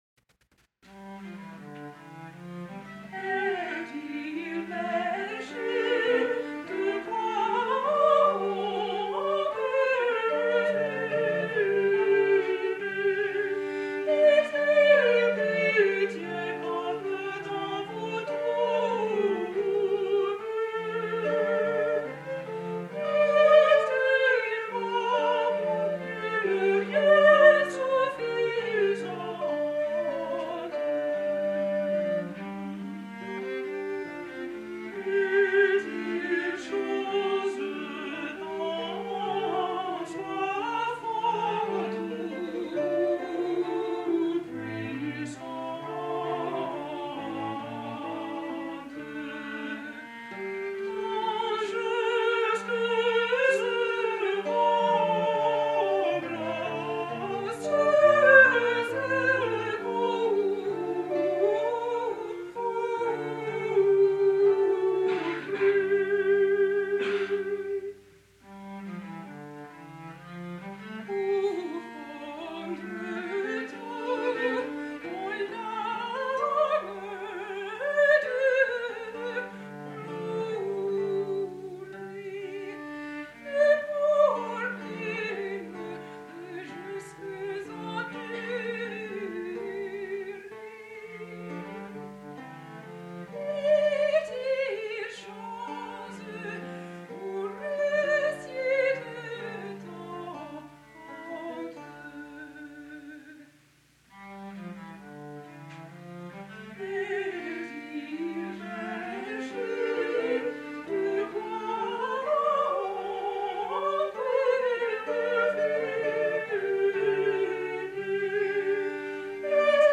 Refrain is sung by duo . . . verse by solo.
Chamber Consort
soprano
alto
viol
recorder
lute.